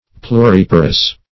Search Result for " pluriparous" : The Collaborative International Dictionary of English v.0.48: Pluriparous \Plu*rip"a*rous\, a. [Pluri- + L. parere to bring forth.]
pluriparous.mp3